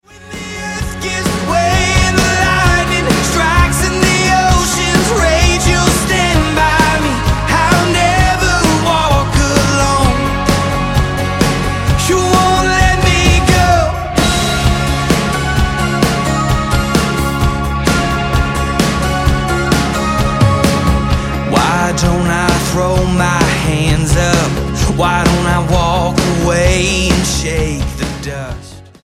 Country Single
Style: Rock